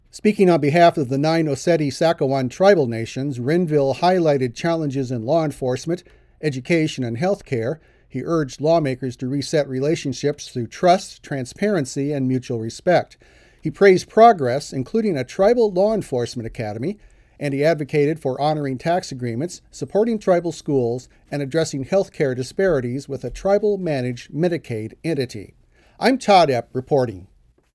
files this report.